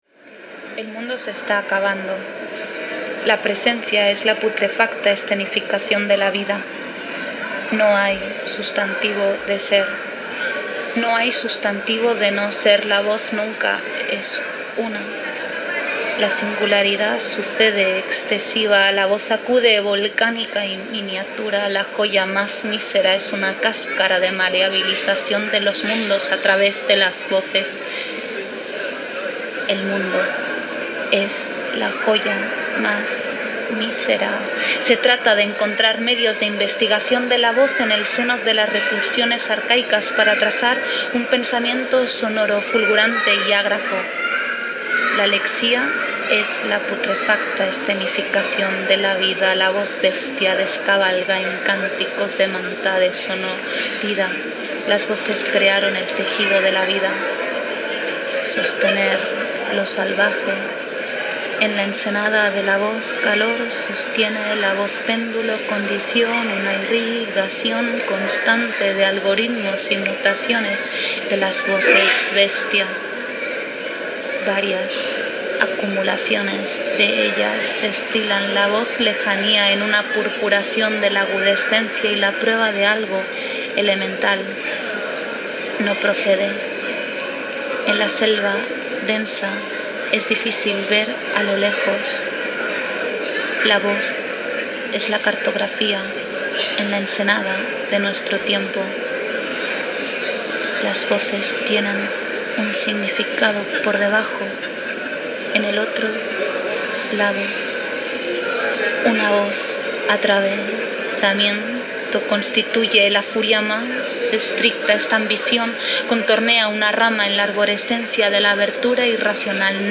Recitado por la autora en un aeropuerto